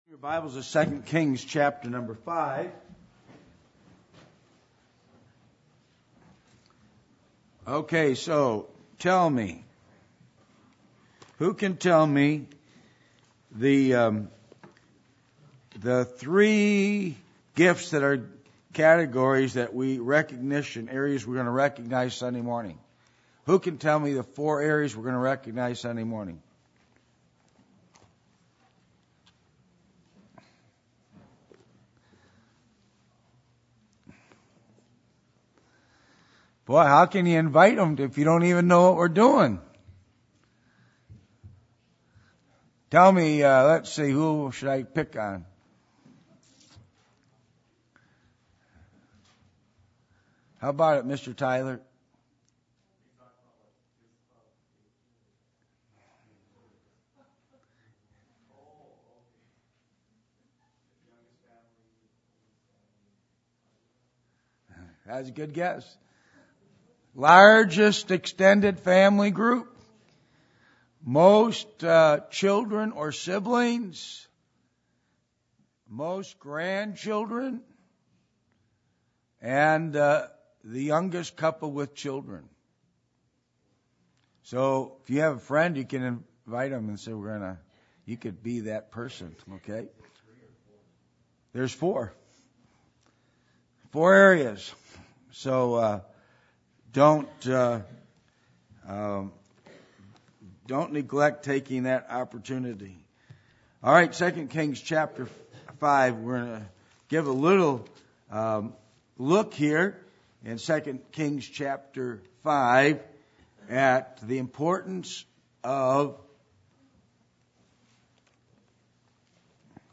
Passage: 2 Kings 5:1-6 Service Type: Midweek Meeting %todo_render% « Wisdom